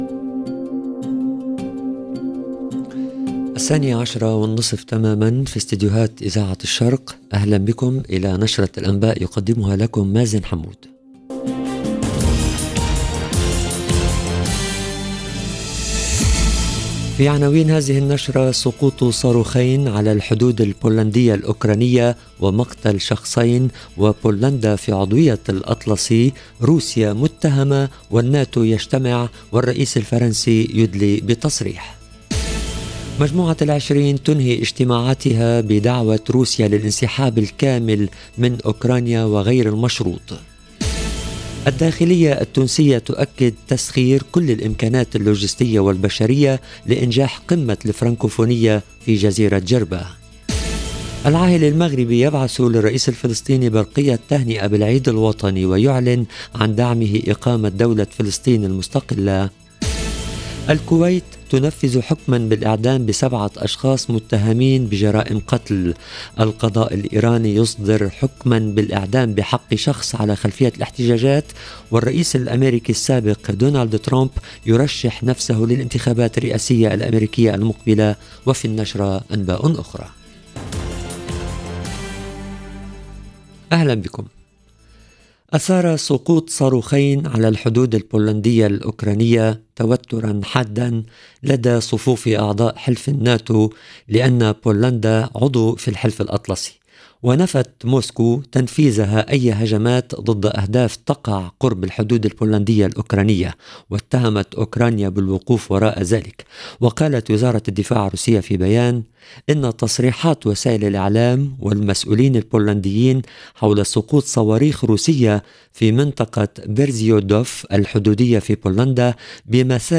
LE JOURNAL EN LANGUE ARABE DE MIDI 30 DU 16/11/22